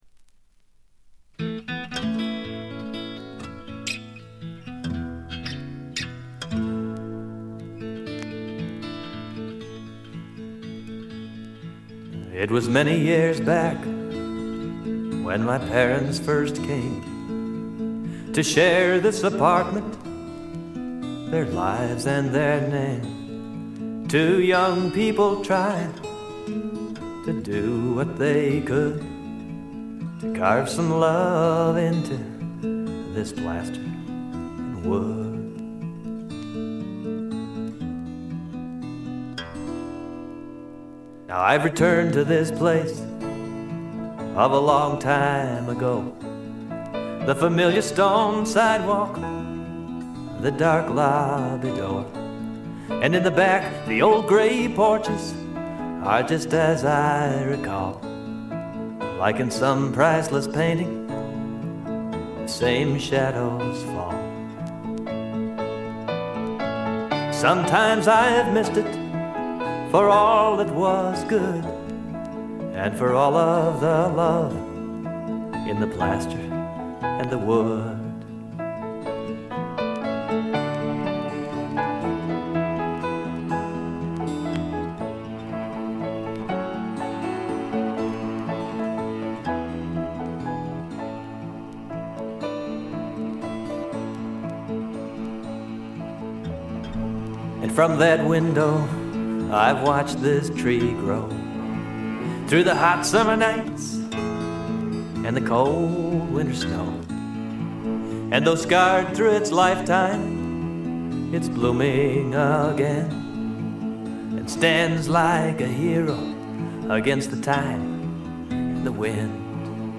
部分試聴ですがほとんどノイズ感無し。
さて内容は85年という時代を感じさせないフォーキーな好盤に仕上がっております。
試聴曲は現品からの取り込み音源です。